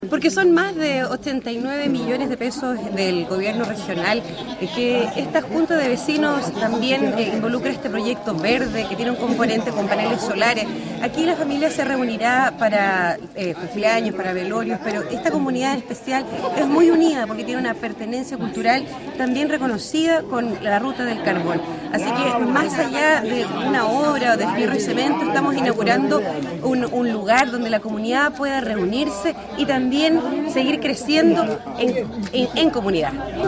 La consejera regional Andrea Iturriaga destacó que son más de 89 millones de pesos del gobierno regional y que esta junta de vecinos también involucra este proyecto verde que tiene un componente con paneles solares.